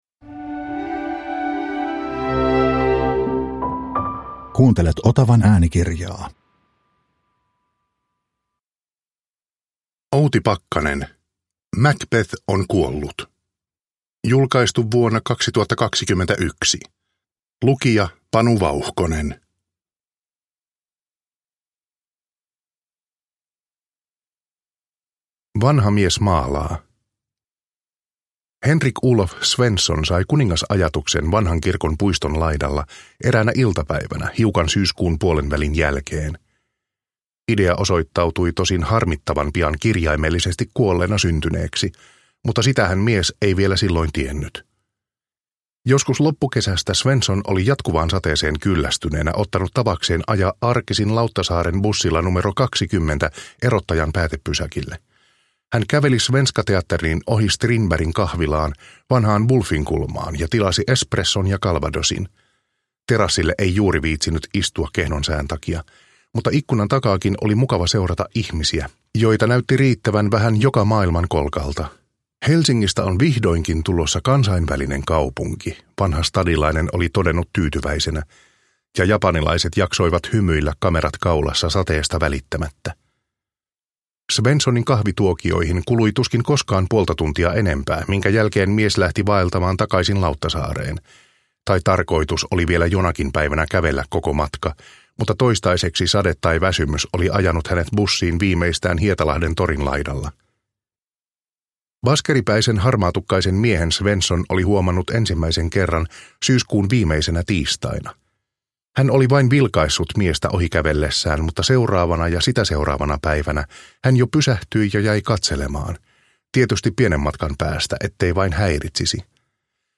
Macbeth on kuollut – Ljudbok – Laddas ner